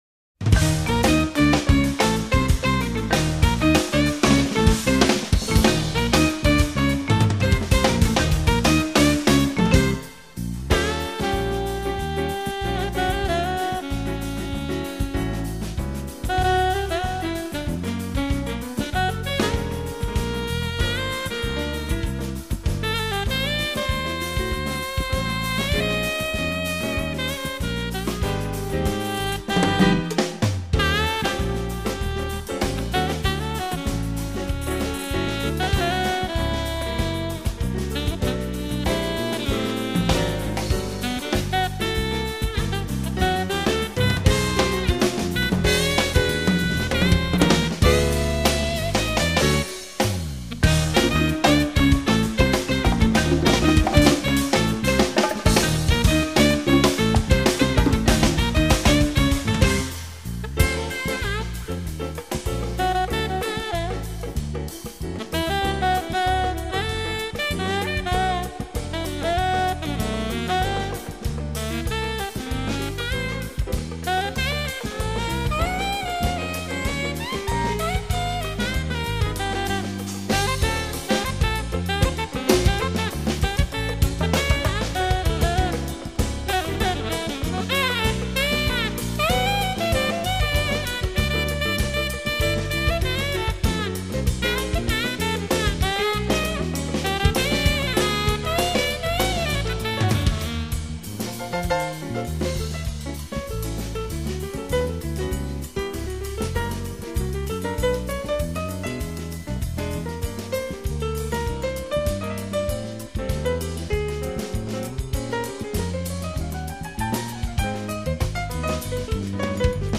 quintet